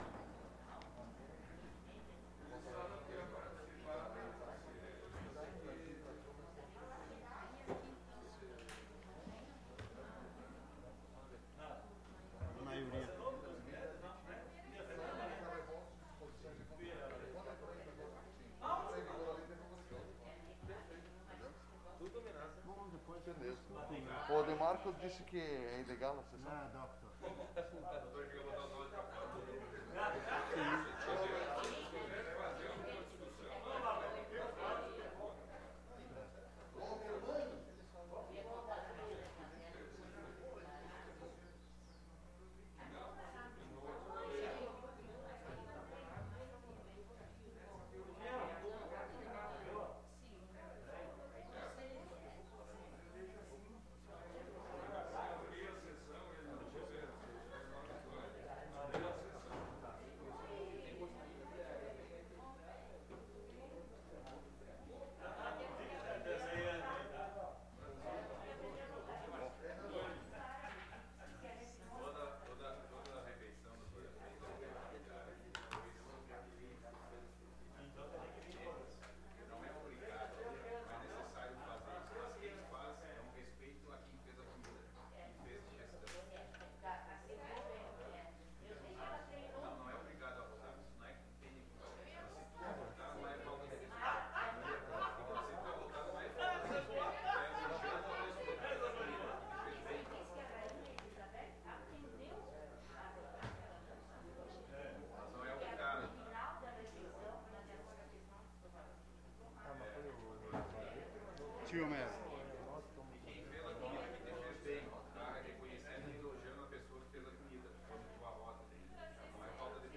Sessão Extraordinária dia 28 de Dezembro de 2020 - Sessão 45